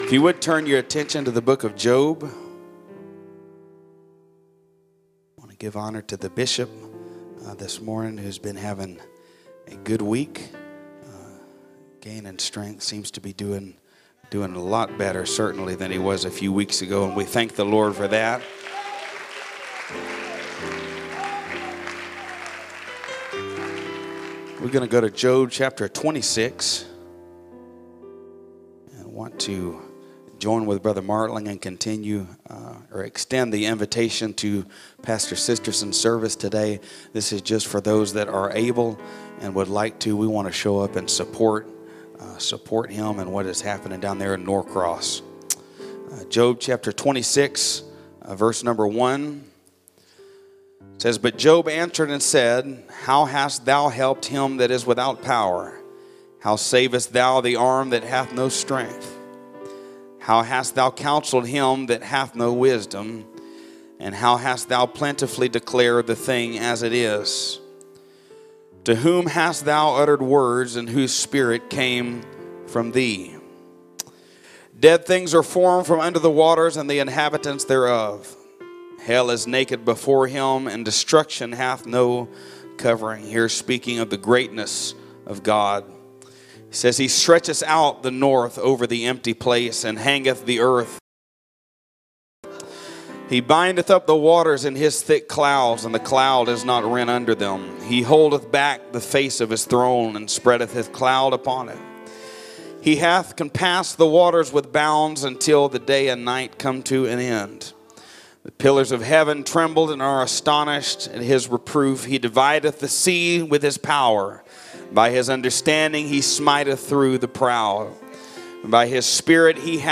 First Pentecostal Church Preaching 2022